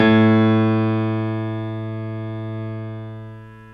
55p-pno12-A1.wav